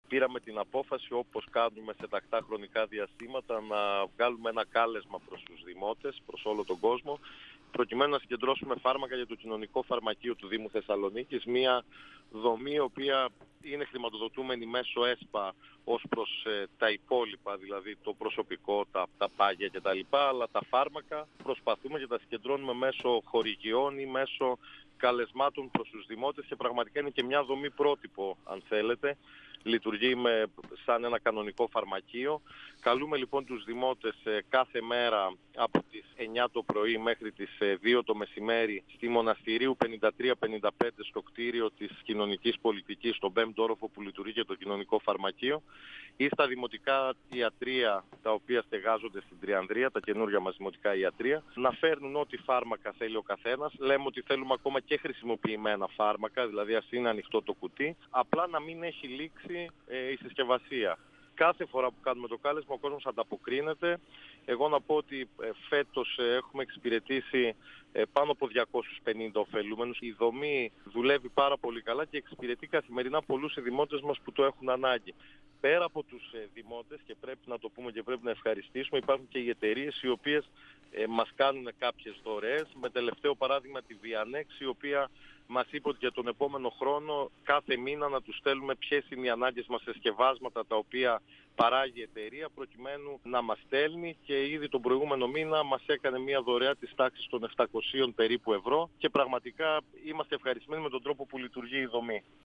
Ο  αντιδήμαρχος Κοινωνικής Πολιτικής και Αλληλεγγύης δήμου Θεσσαλονίκης, Πέτρος Λεκάκης,  στον 102FM του Ρ.Σ.Μ. της ΕΡΤ3
Συνέντευξη